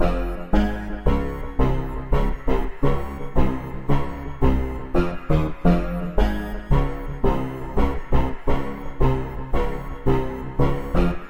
标签： 85 bpm Rap Loops Bass Loops 3.80 MB wav Key : Unknown
声道立体声